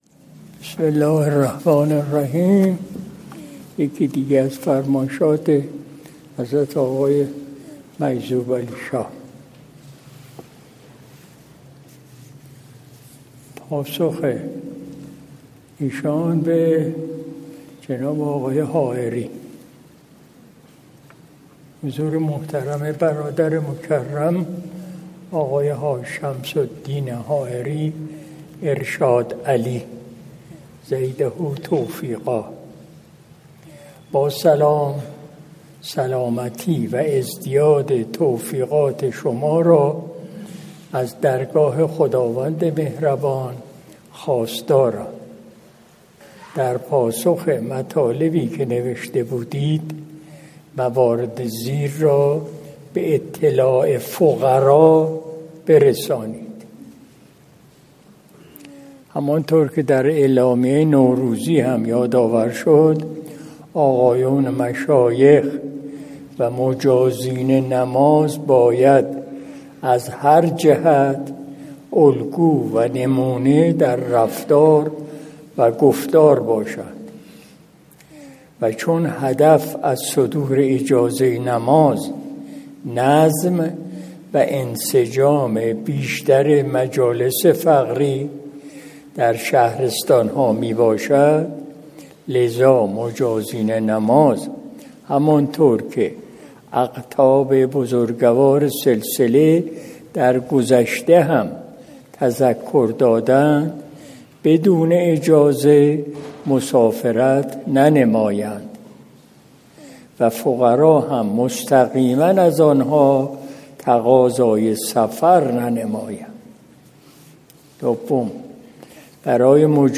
قرائت بیانیه‌های حضرت آقای حاج دکتر نورعلی تابنده «مجذوب‌علیشاه» طاب‌ثراه
مجلس شب دوشنبه